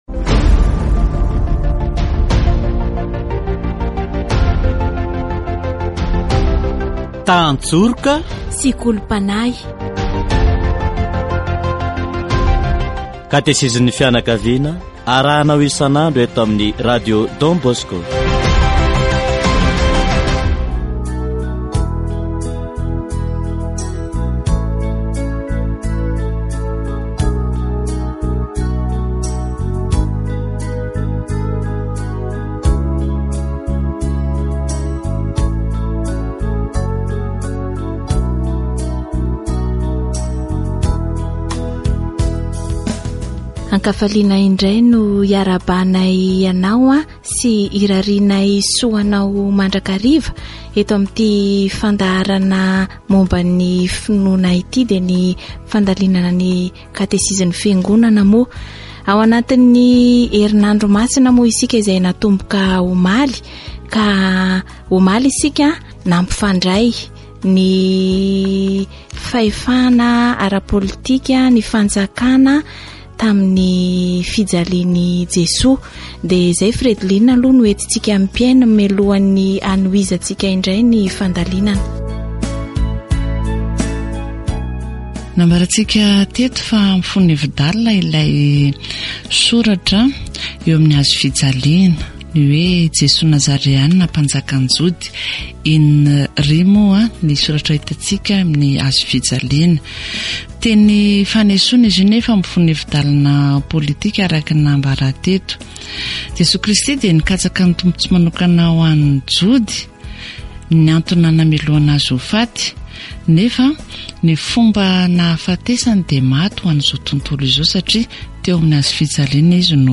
Katesizy momba ny politika araka ny fijerin'ny Eglizy